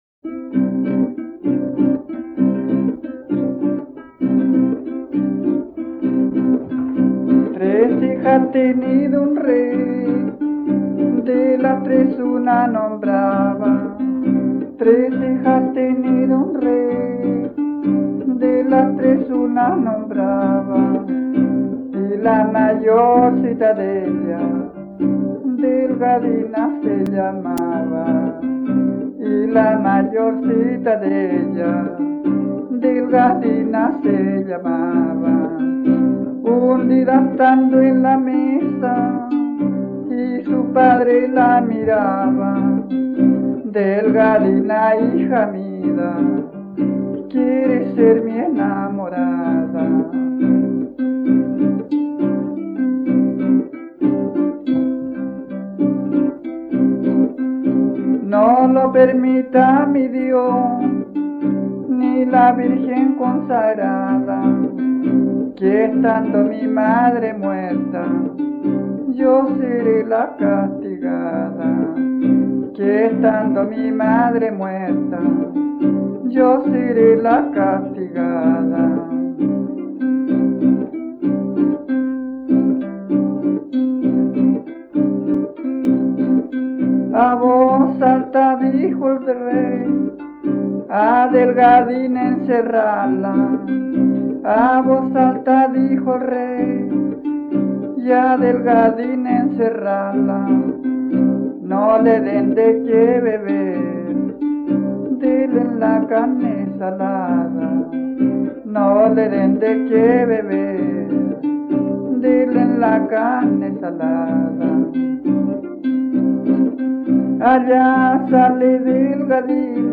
Romance en forma de vals que trata el tema tradicional de "Delgadina".
quien se acompaña con una guitarra afinada con la tercera alta.
Música tradicional
Folklore